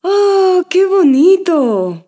Alabanza de una mujer: ¡Qué bonito!
exclamación
locución
mujer
Sonidos: Voz humana